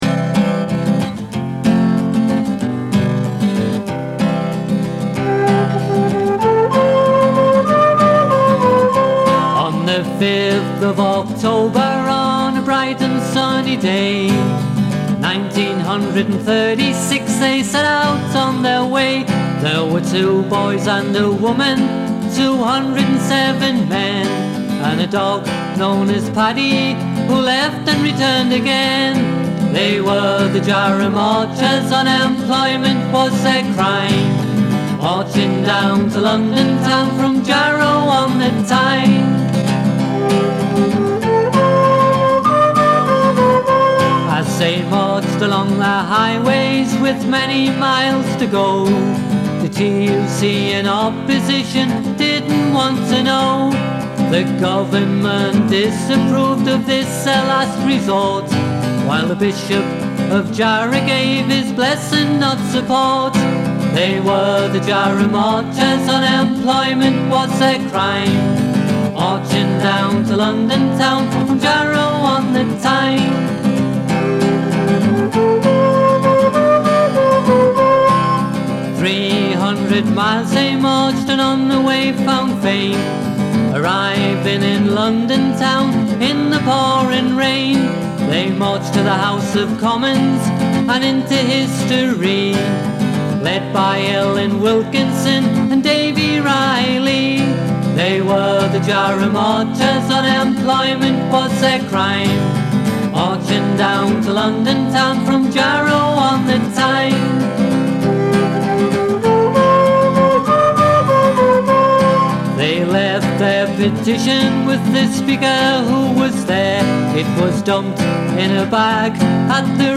Vocals & Guitar
Recorder
Flute
Piano Accordion.